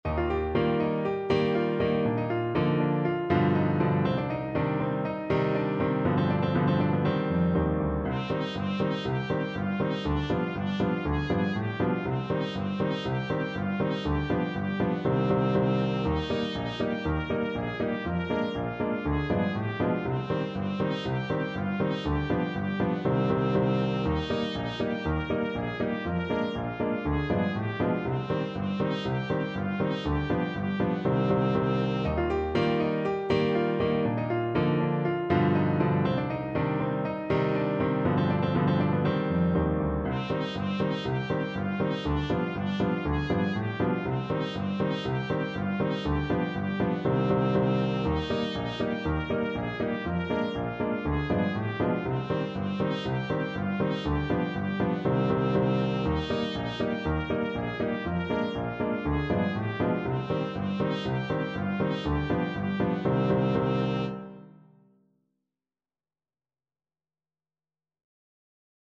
Trumpet
4/4 (View more 4/4 Music)
B4-Bb5
C minor (Sounding Pitch) D minor (Trumpet in Bb) (View more C minor Music for Trumpet )
Allegro (View more music marked Allegro)
Traditional (View more Traditional Trumpet Music)